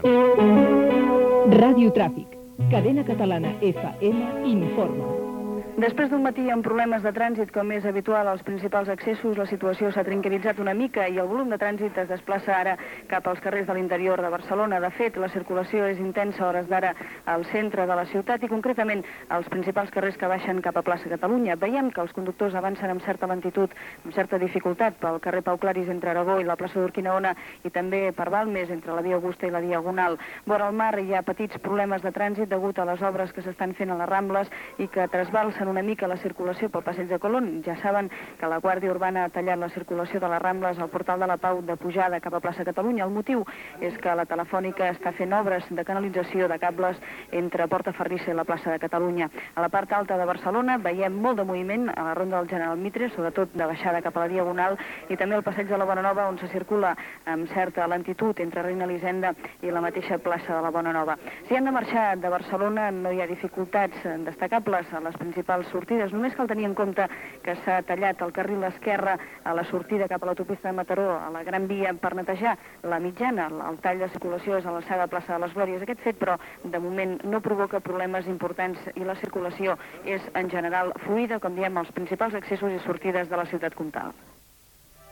Indicatiu de l'emissora, estat del tràndit a Barcelona
Informatiu
FM